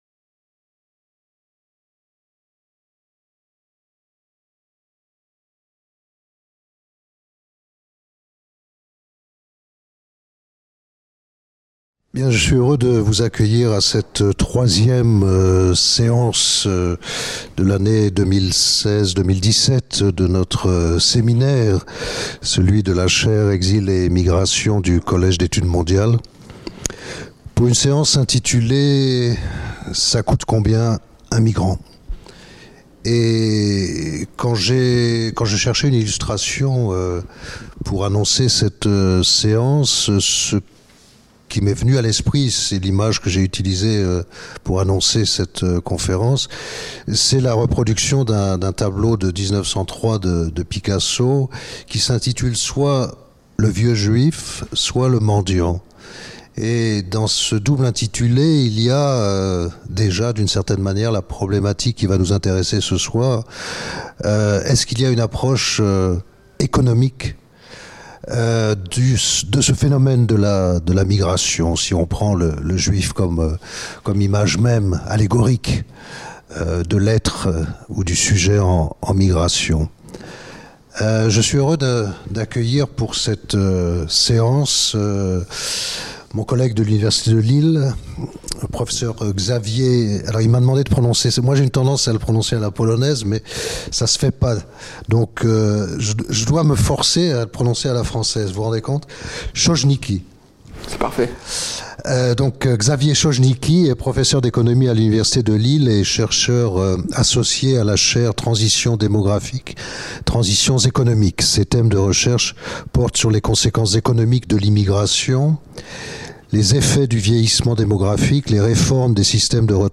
Séminaire de la Chaire Exil et migrations